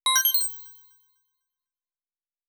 Coins (28).wav